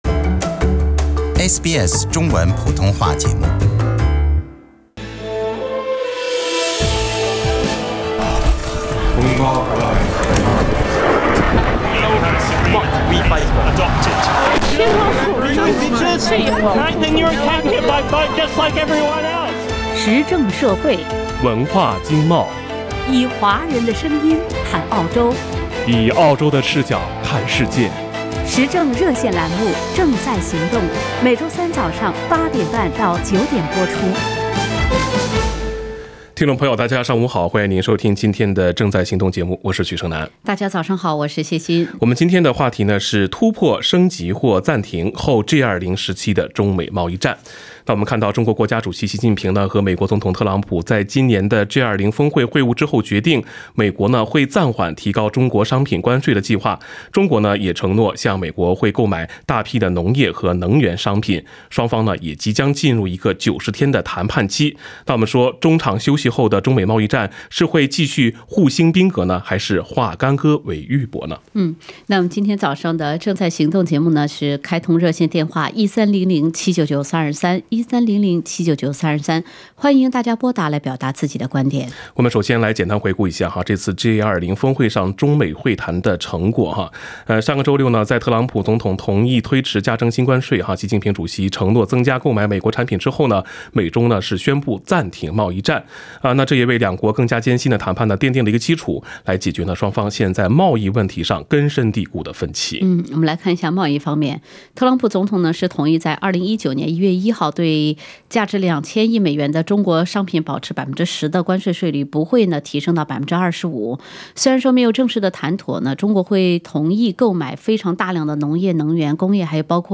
Action Talkback US China Trade War in Post-G20 Era